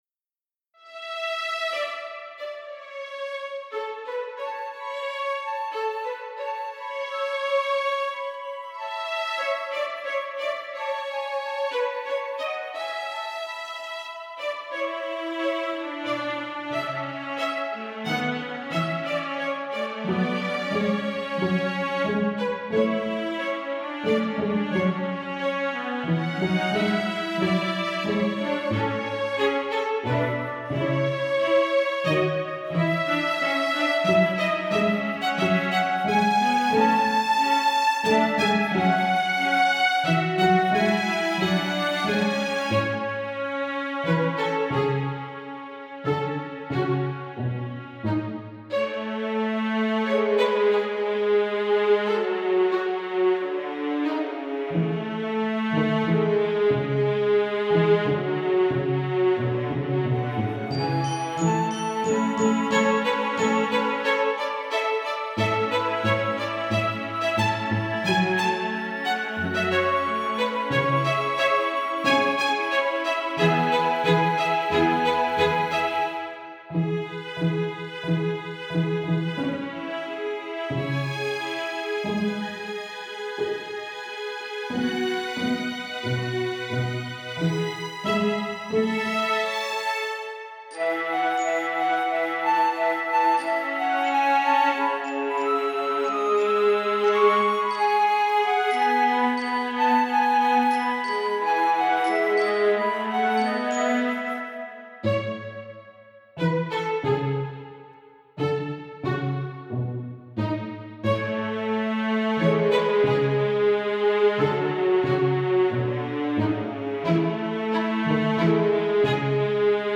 Peaceful Music
But I'm back this month with a peaceful song that may be appropriate for town music (or potentially other areas as well).My wife named it based on a phrase found in a Murakami Haruki book.